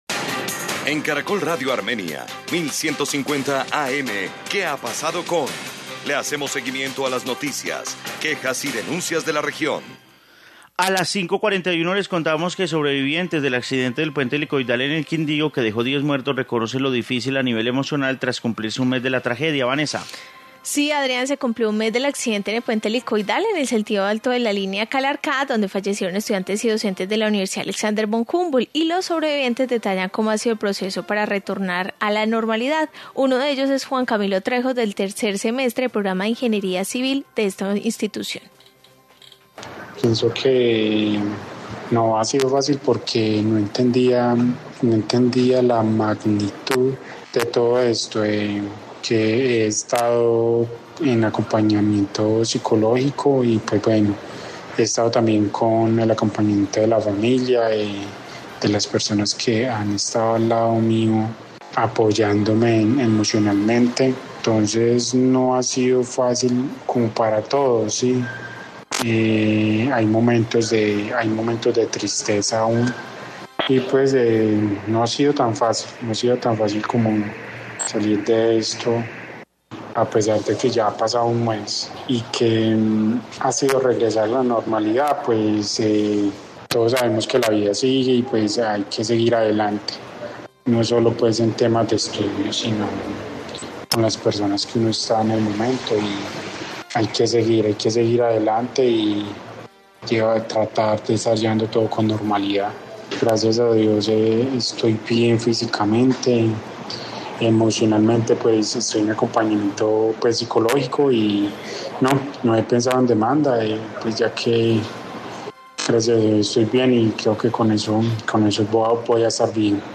Informe sobre sobrevivientes de accidente en puente helicoidal